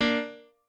piano8_5.ogg